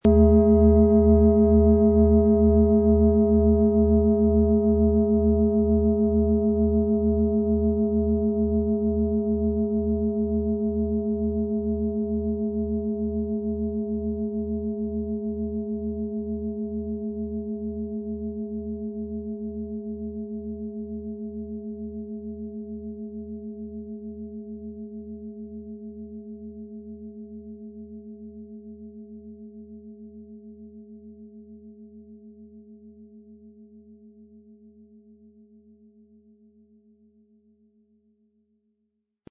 Planetenschale® Neues beginnen & Ruhiger werden und sein mit Biorhythmus Seele, Ø 26,8 cm, 1500-1600 Gramm inkl. Klöppel
Um den Originalton der Schale anzuhören, gehen Sie bitte zu unserer Klangaufnahme unter dem Produktbild.
Der passende Schlegel ist umsonst dabei, er lässt die Schale voll und harmonisch tönen.
MaterialBronze